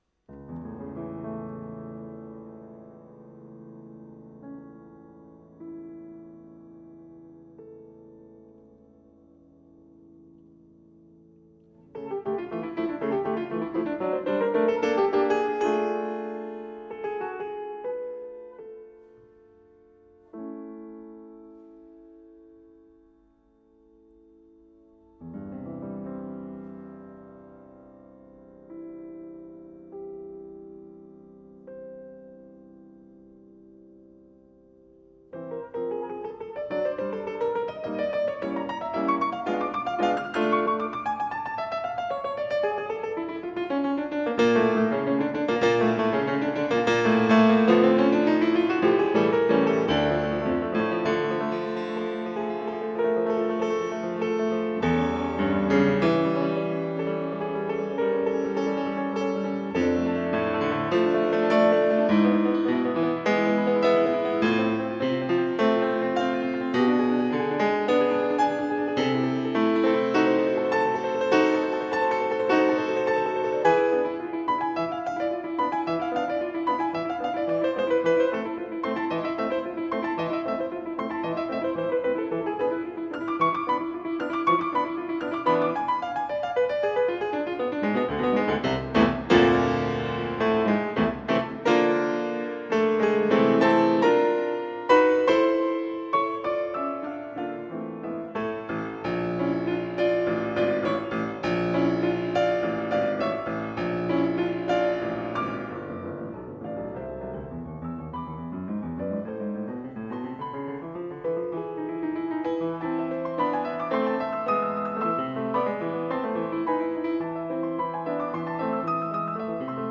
Piano
Classical
piano-sonata-17-op-31-2.mp3